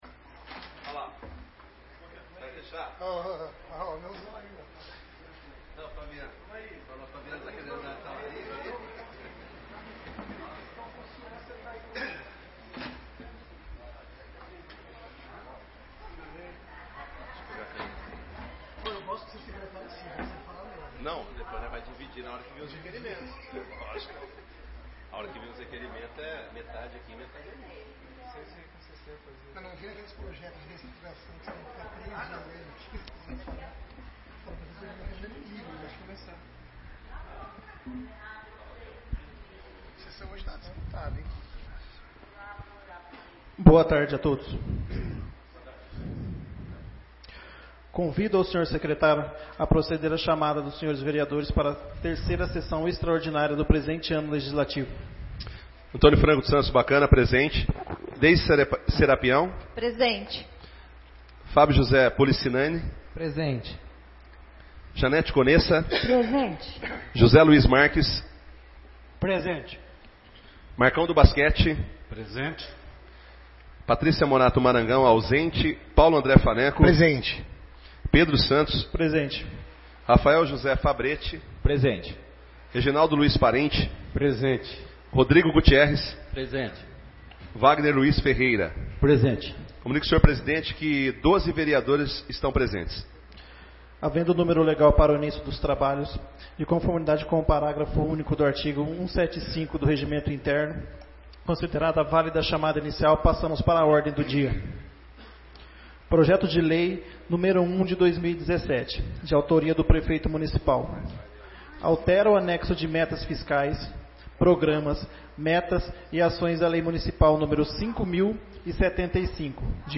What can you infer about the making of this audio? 3ª e 4ª Sessões Extraordinárias de 2017